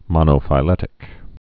(mŏnō-fī-lĕtĭk)